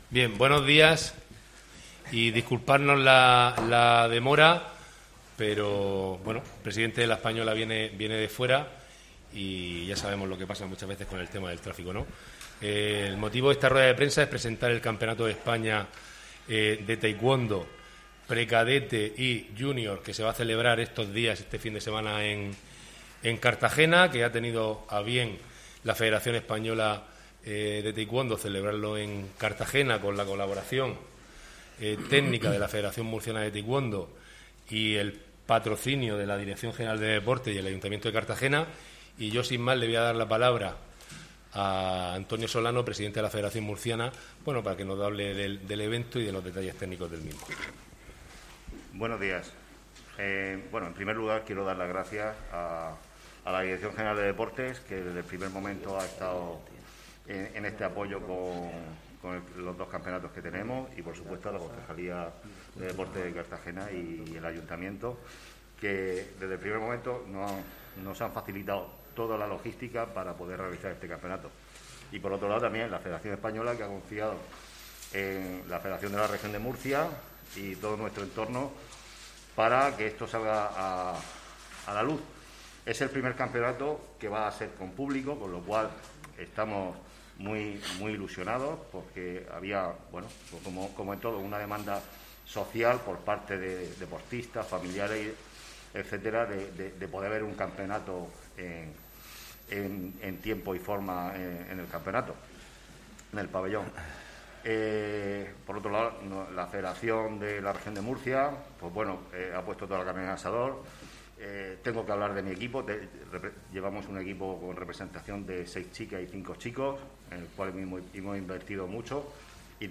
Audio: Presentaci�n del Campeonato de Taekwondo (MP3 - 7,57 MB)